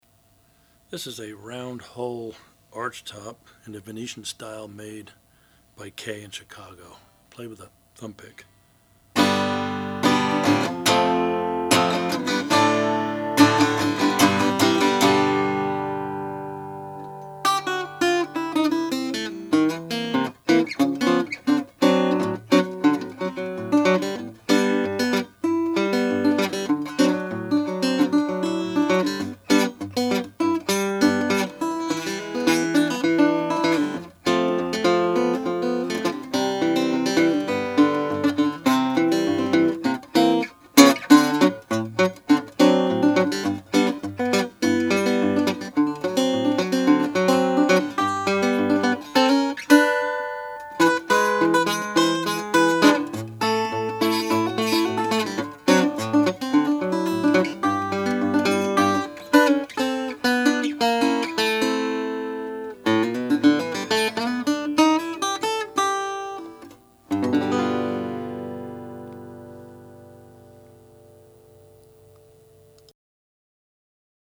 The top is laminated spruce pressed into an arch and features X-bracing.
These round-hole archtops surely have a unique sound, favoring the nasal archtop tone, with strong mids. It's fun to fingerpick ragtime and blues, but it really shines when played in a jazz or rhythm style with a pick.